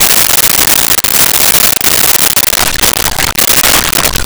Splashes Soft 01
Splashes Soft 01.wav